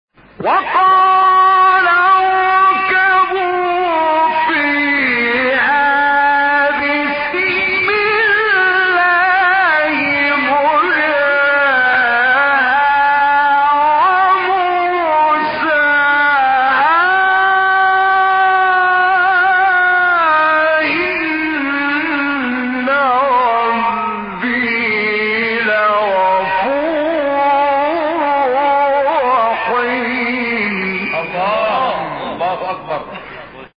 گروه شبکه اجتماعی: فرازهایی از تلاوت قاریان ممتاز مصری در زیر ارائه می‌شود.
فرازی از راغب مصطفی غلوش/ سوره مریم به تقلید از محمد رفعت